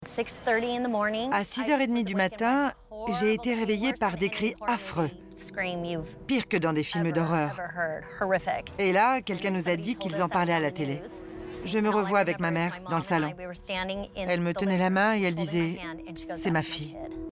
Timbre : Énergique Paniqué